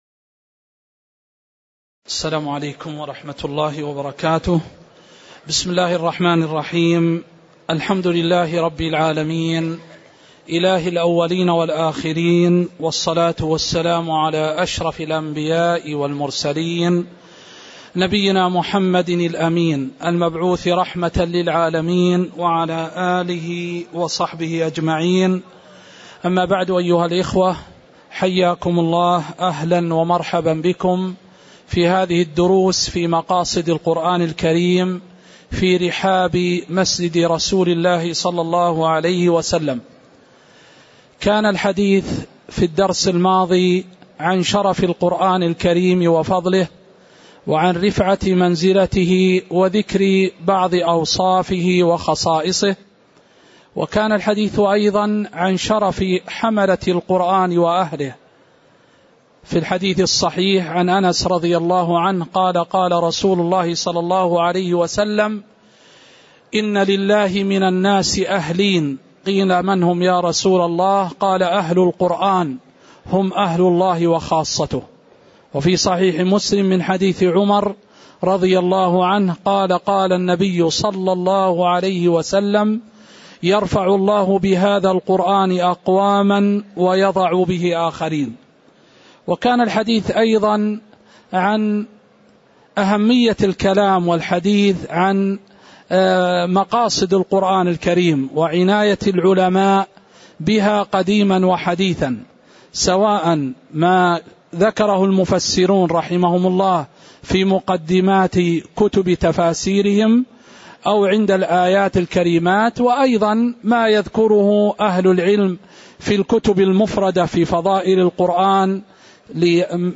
تاريخ النشر ٣ رجب ١٤٣٨ المكان: المسجد النبوي الشيخ